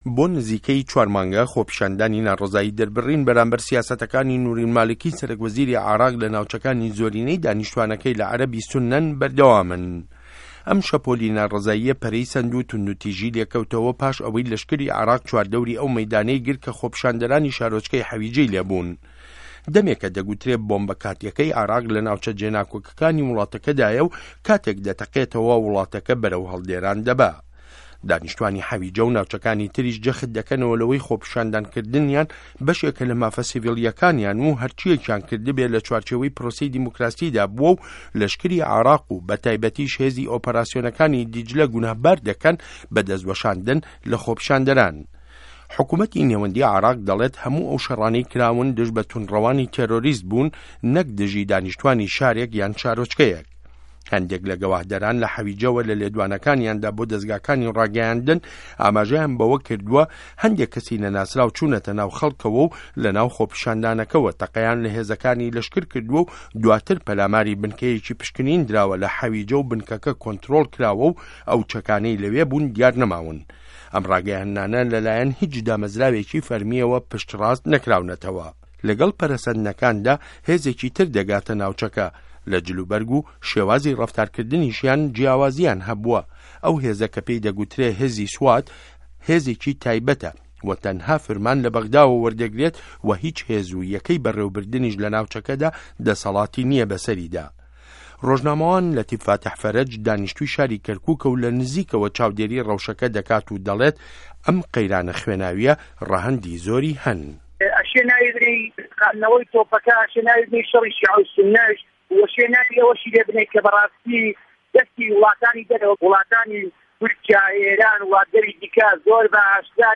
ڕاپۆرتی شه‌ڕه‌کانی حه‌ویجه‌ سه‌ره‌تایه‌ک بۆ شه‌ڕی ناوخۆی عێراق